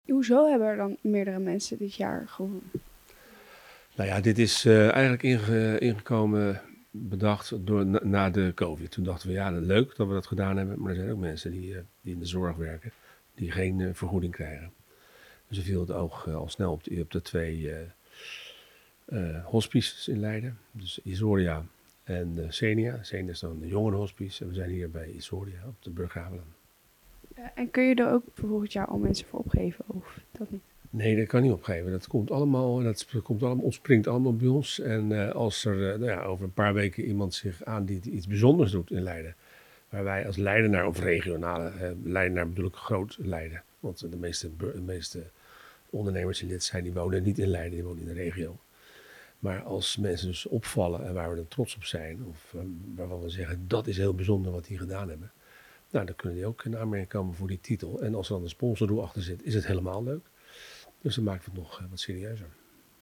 Verslaggever
in gesprek met